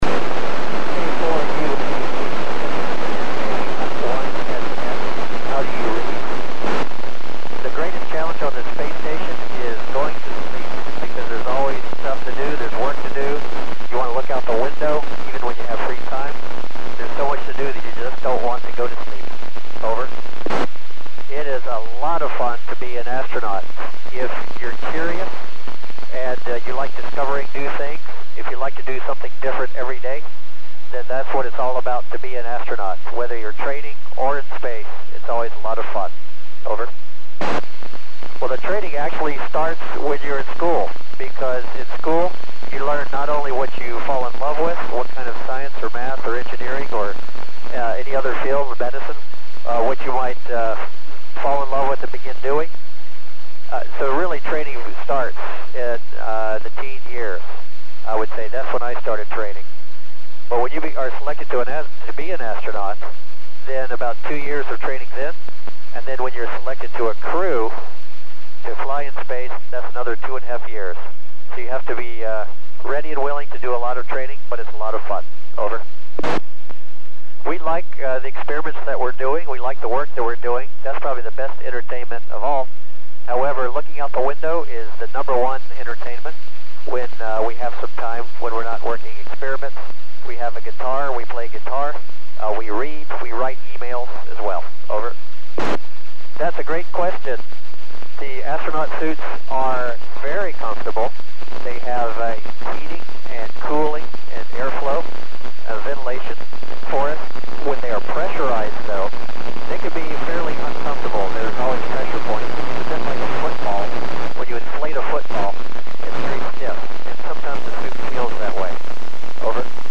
Dr. Tom Marshburn (NA1SS) speaks to students at the University of Alabama at Huntsville Space Hardware Club, Huntsville , AL, at 1549 UTC on 21 March 2013 via K4UAH.